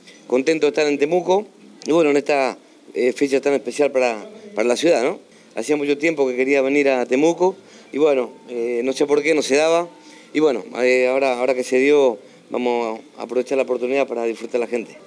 Miguel-Angel-DAnnibale-vocalista-Amar-Azul.mp3